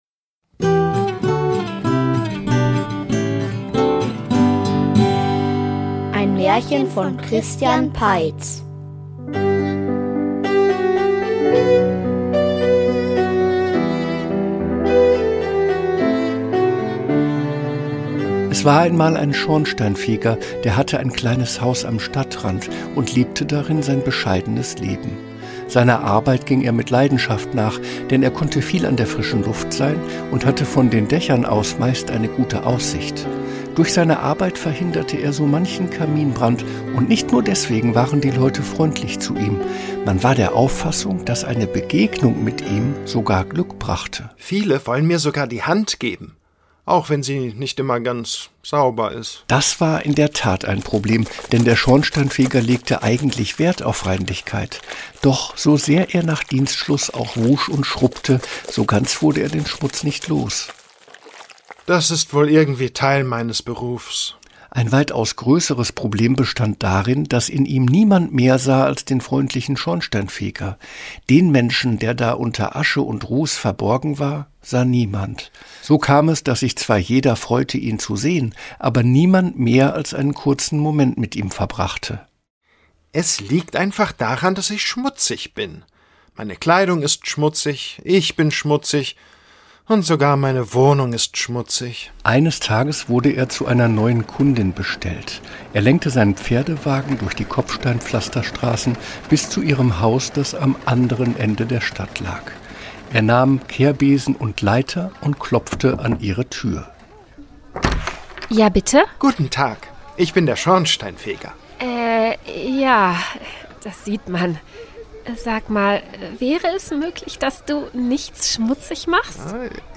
Der Schornsteinfeger --- Märchenhörspiel #60 ~ Märchen-Hörspiele Podcast